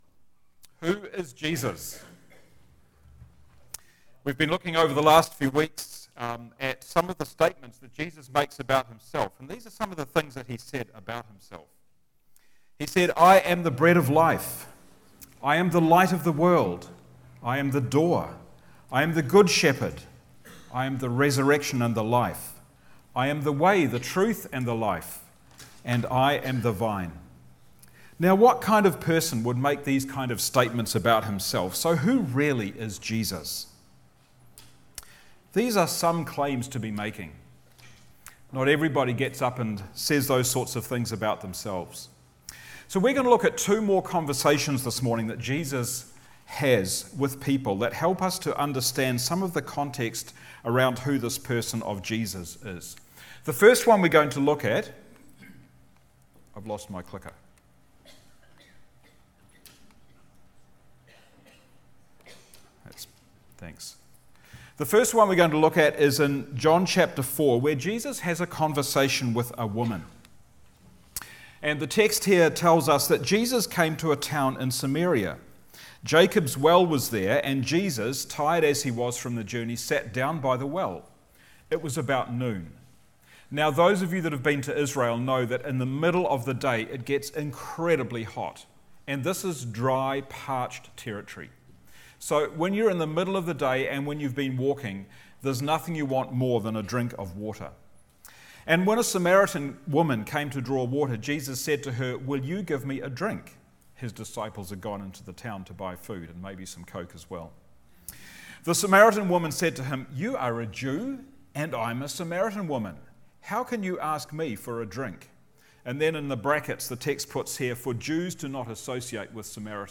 Sermons | Titirangi Baptist Church
Guest Speaker